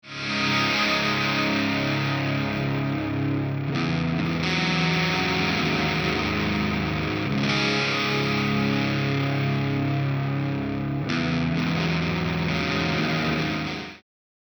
JN Brit Ambience I
No post processing was added.